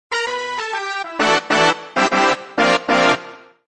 BRASS1.ogg